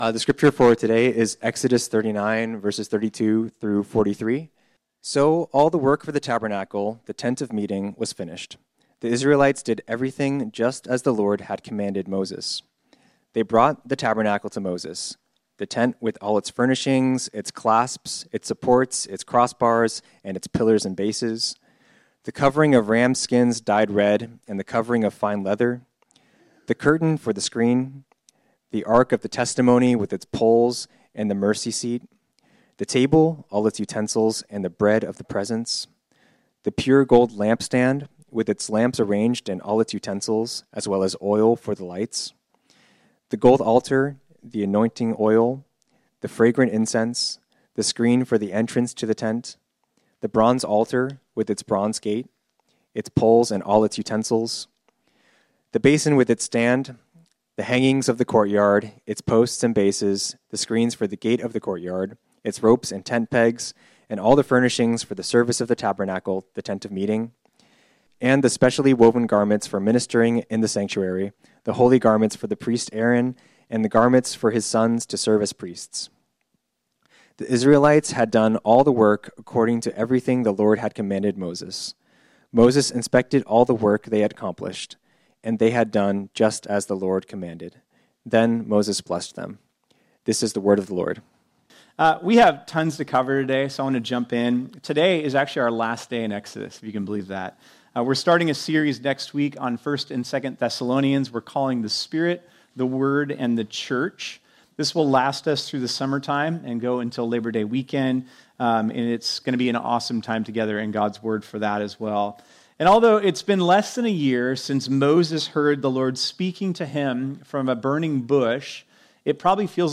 This sermon was originally preached on Sunday, May 25, 2025.